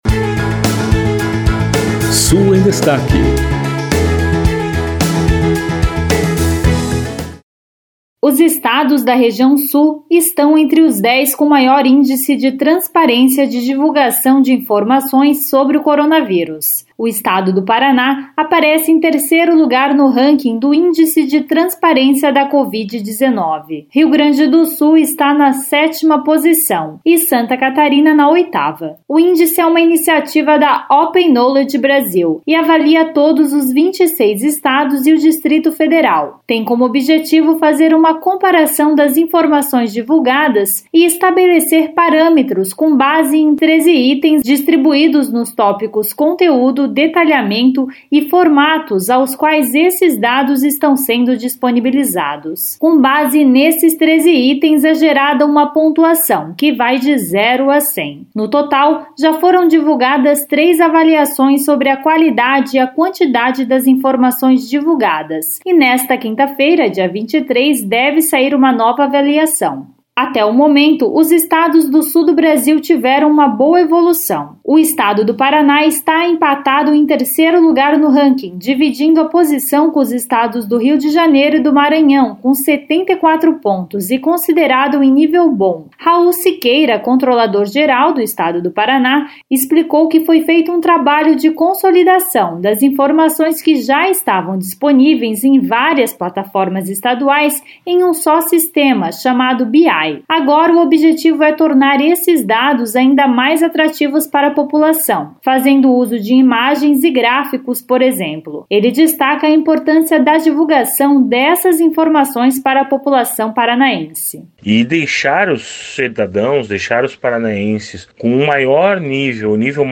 O governador de Santa Catarina, Carlos Moisés, destacou que o estado está divulgando os números de casos da covid 19 em um site criado especificamente para isso e que a transparência para ele é a divulgação de dados confiáveis.